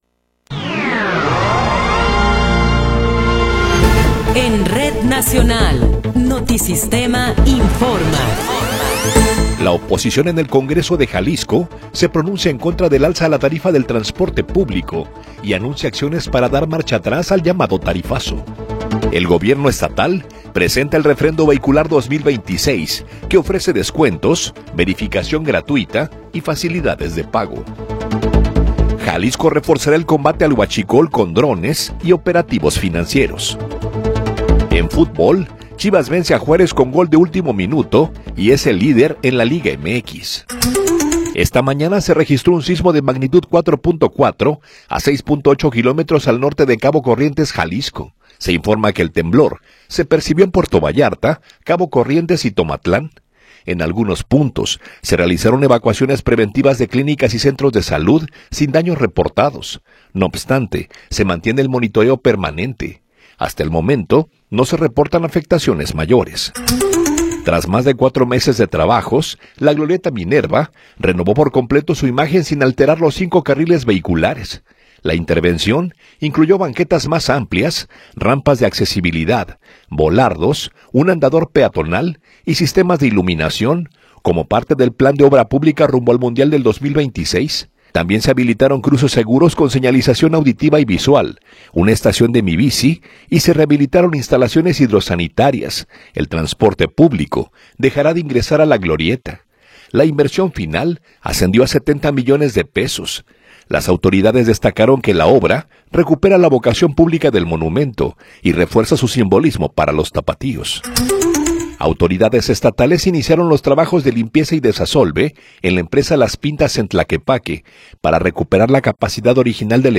Noticiero 9 hrs. – 14 de Enero de 2026